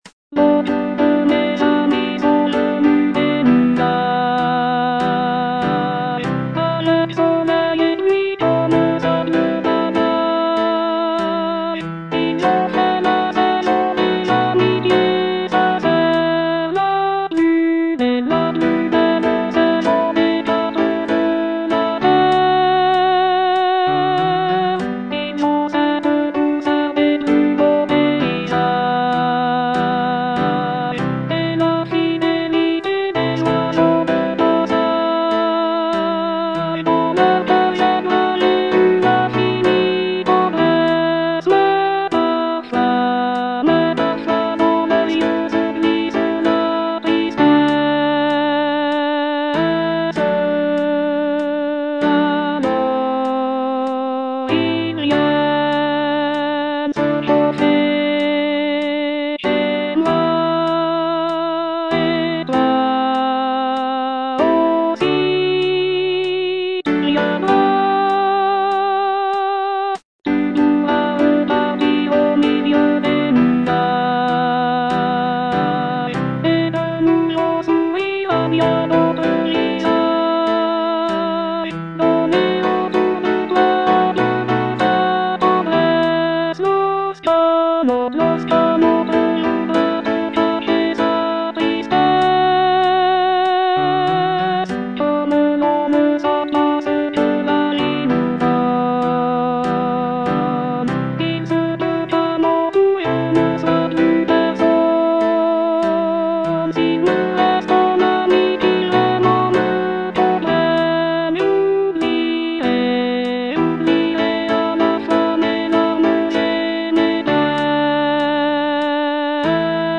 Alto I (Voice with metronome)
piece for choir